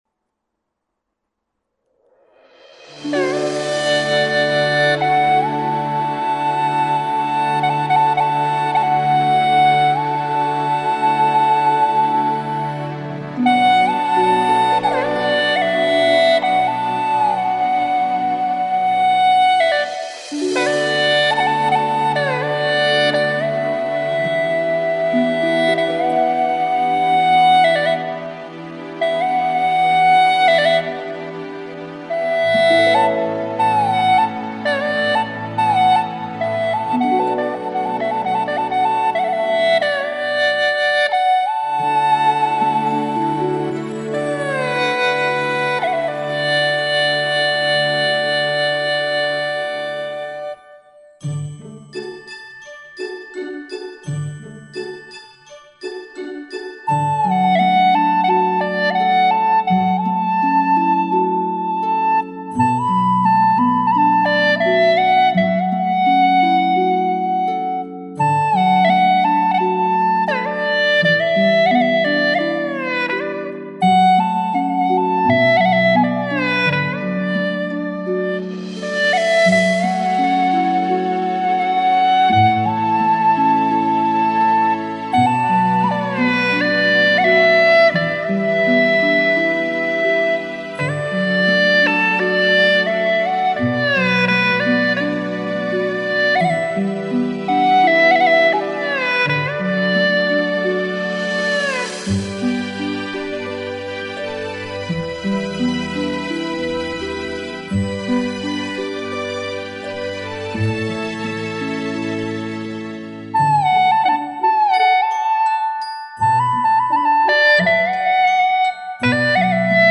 调式 : D 曲类 : 独奏
优美动听的旋律表现了春天给人们带来了吉祥、友谊和幸福。